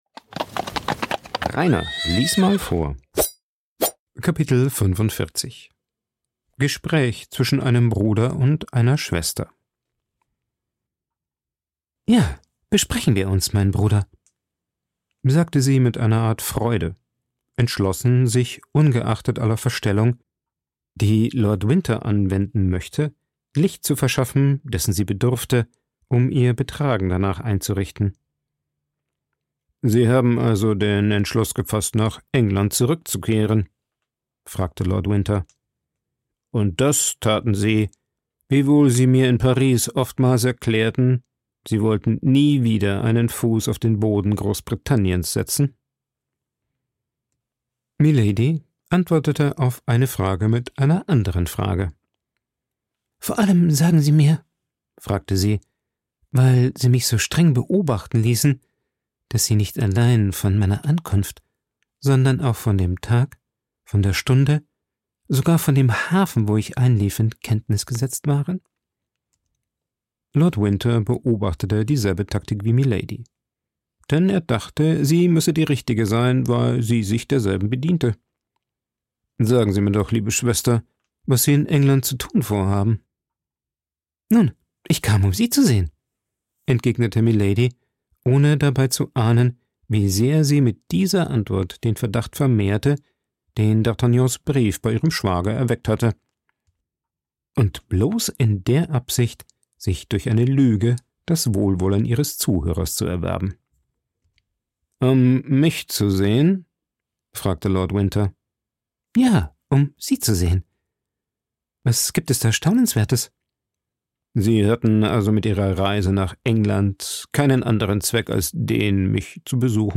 Vorgelesen
aufgenommen und bearbeitet im Coworking Space Rayaworx, Santanyí, Mallorca.